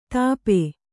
♪ tāpe